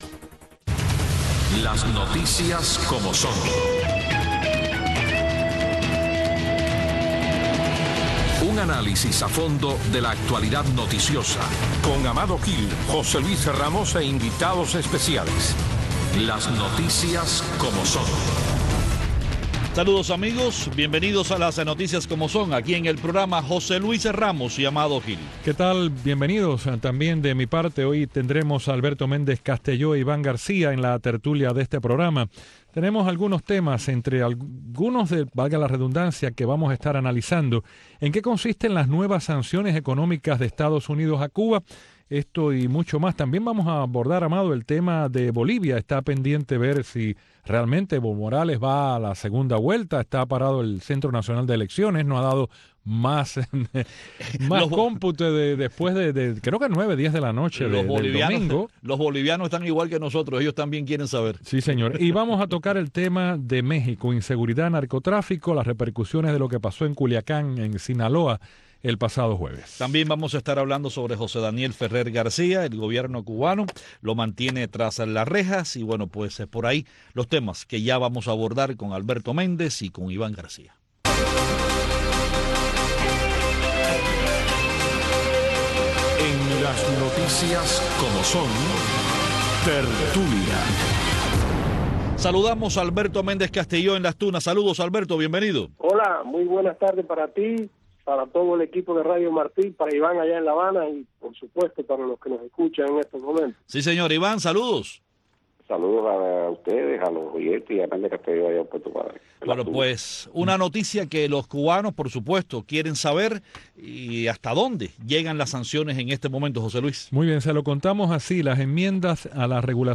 conversan esta tarde con los periodistas